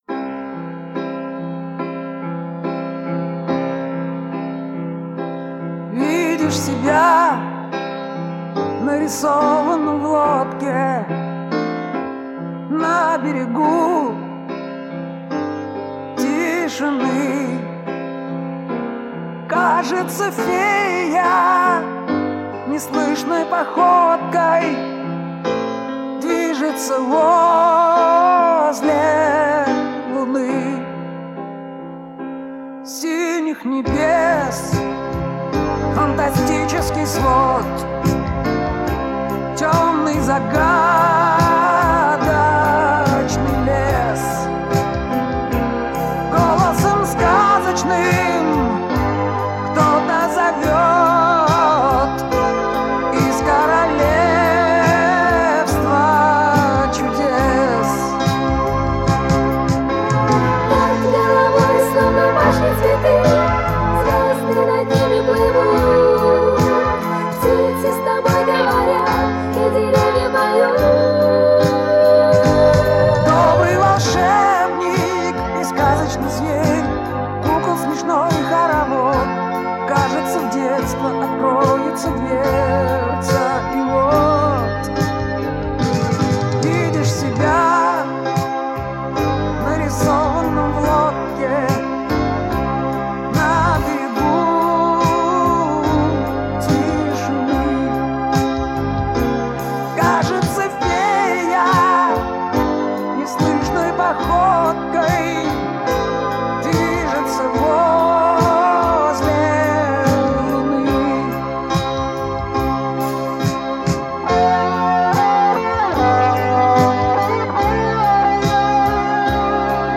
ударные